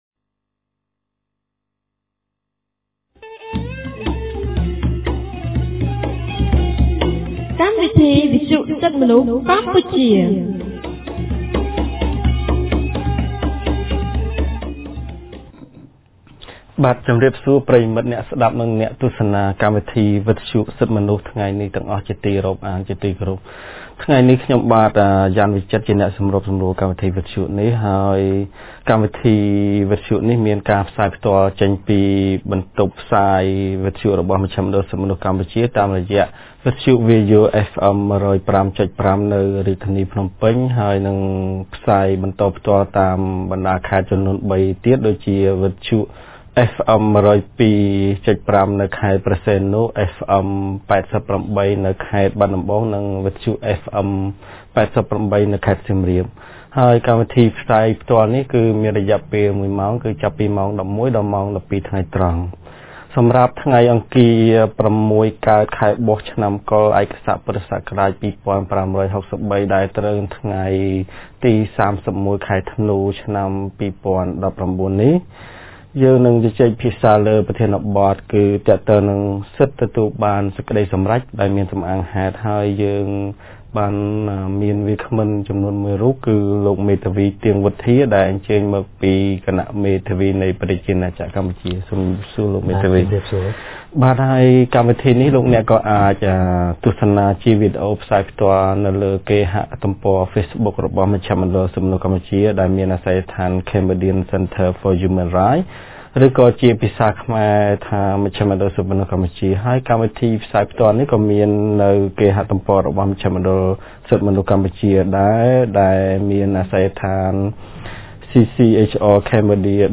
ថ្ងៃអង្គារ ទី៣១ ខែធ្នូ ឆ្នាំ២០១៩ គម្រាងសិទ្ធិទទួលបានការជំនុំជម្រះដោយយុត្តិធម៌នៃមជ្ឈមណ្ឌលសិទ្ធិមនុស្សកម្ពុជា បានរៀបចំកម្មវិធីវិទ្យុក្រោមប្រធានបទស្តីពី សិទ្ធិទទួលបានសេចក្តីសម្រេចដែលមានសំអាងហេតុ។